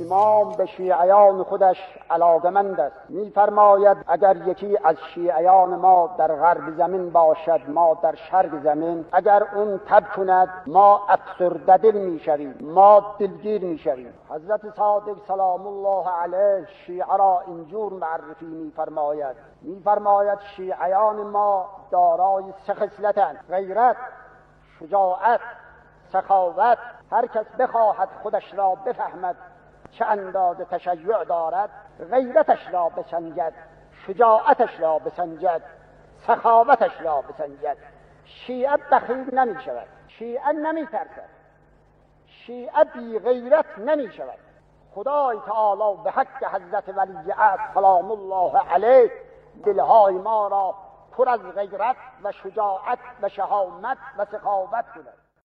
صوت ســـخنرانی:
سخنران: علامه امینى رحمه‌اللّه‌علیه.